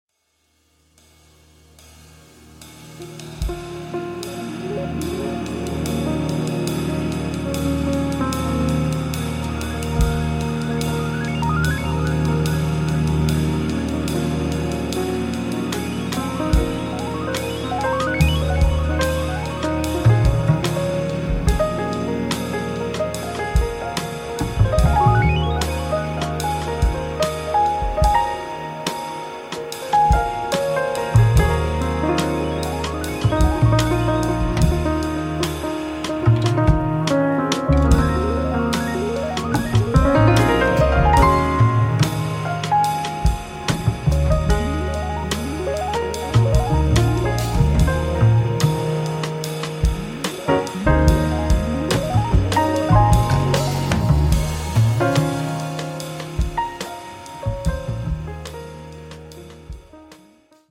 in a classic piano trio setting